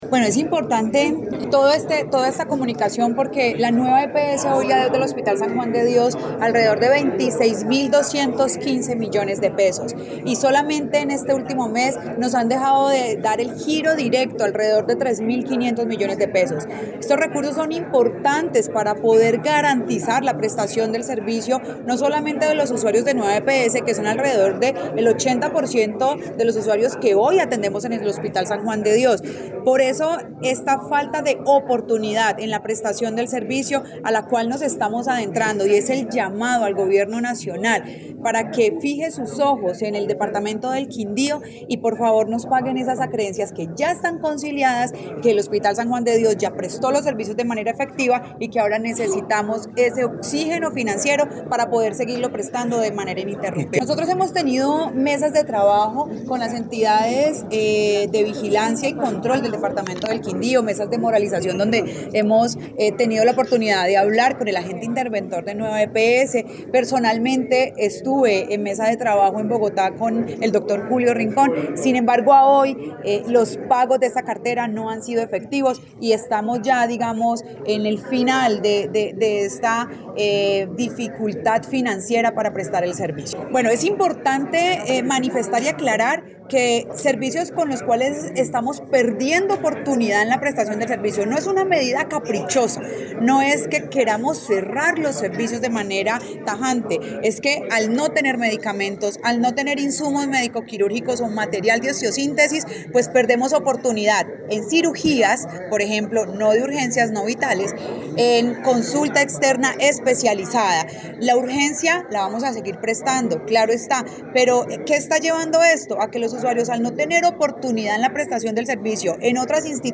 rueda de prensa Nueva EPS: